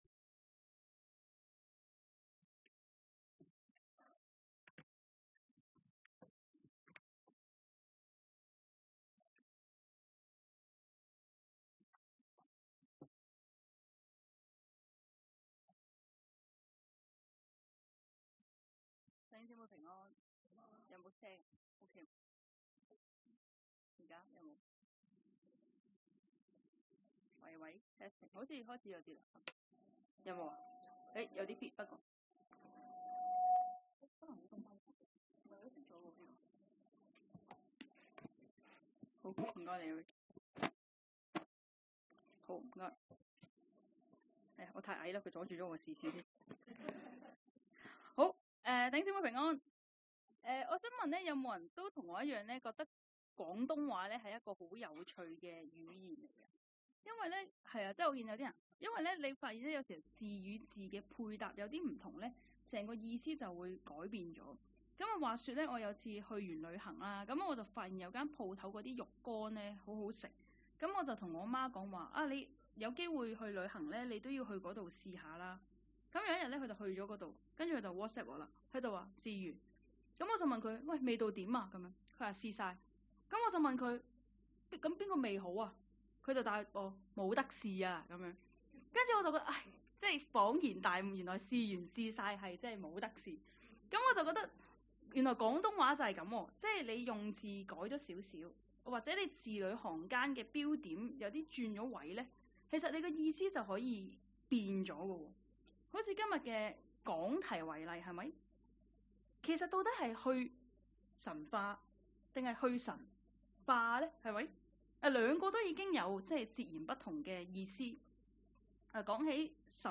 講道